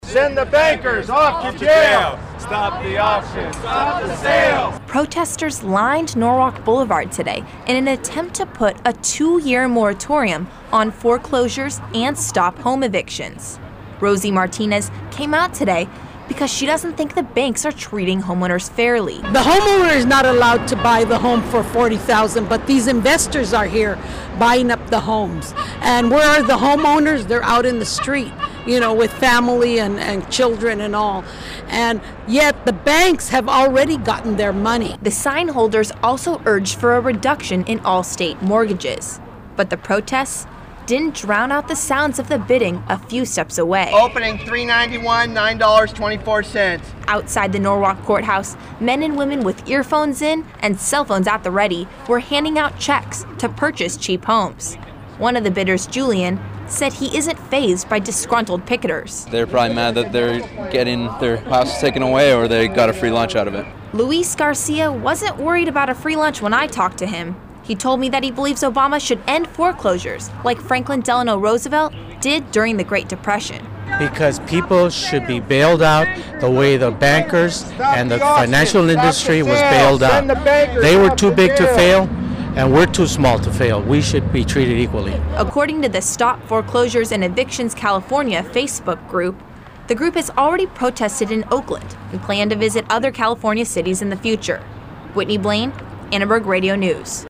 It was a busy day outside the Norwalk County Courthouse Tuesday, where former homeowners pushing to end foreclosures were just a few feet from a foreclosure auction.
Protesters from various groups, including the International Action Center, Mexicana Transnacional and the Service Employees International Union chanted "stop the auction, stop the sale, send the bankers off to jail."